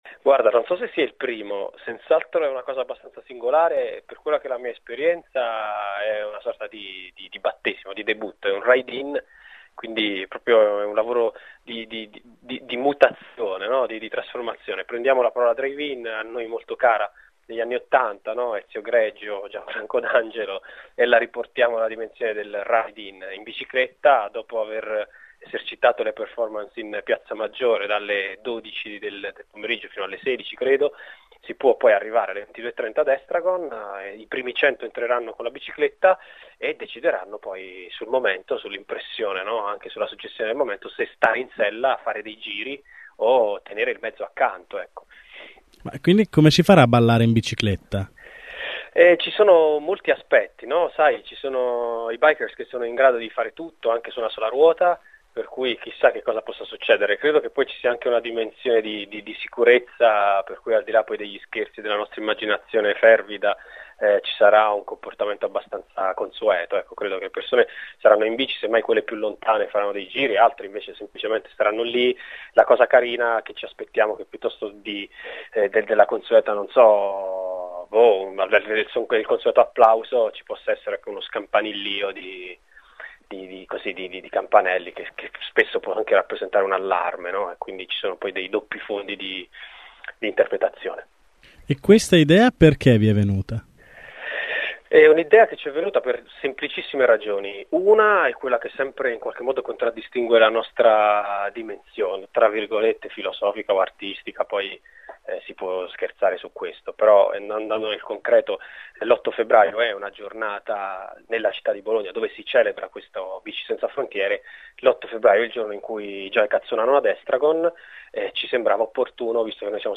abbiamo intervistato